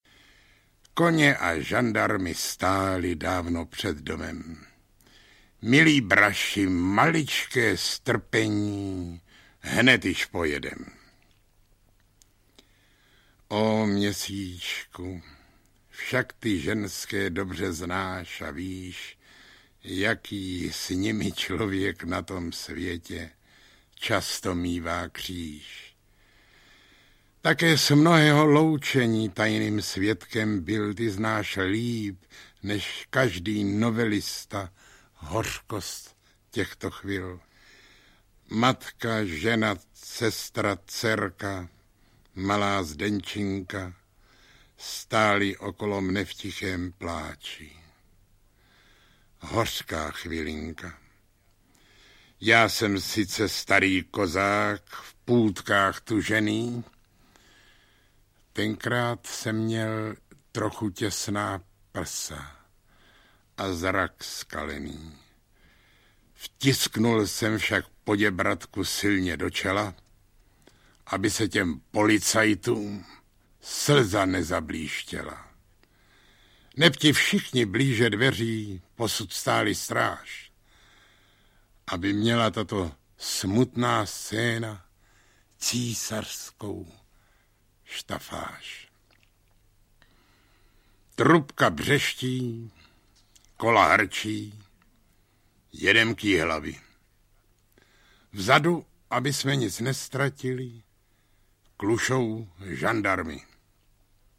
Básně audiokniha
Audiokniha Básně - obsahuje poezii, jejímž autorem je Karel Havlíček Borovský. Recituje Karel Höger, Jan Pivec, Zdeněk Štěpánek.
Ukázka z knihy